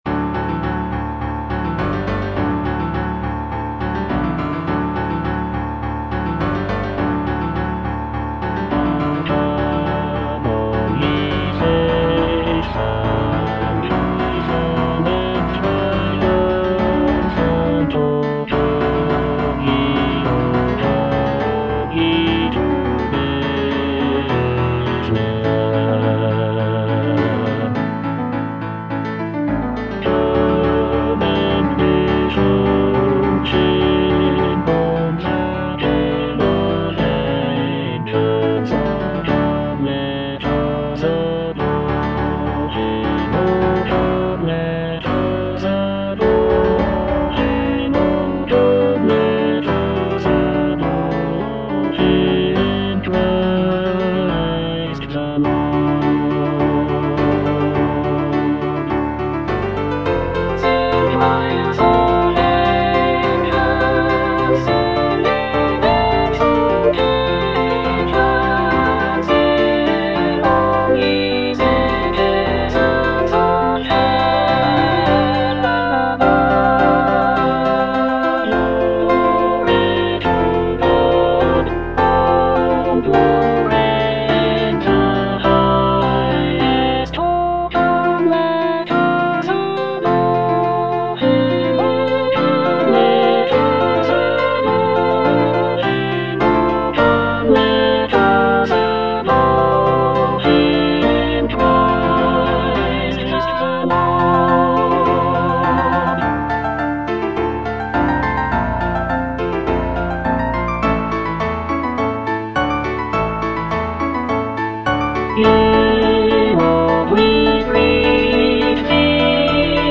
(All voices)
Christmas carol
incorporating lush harmonies and intricate vocal lines.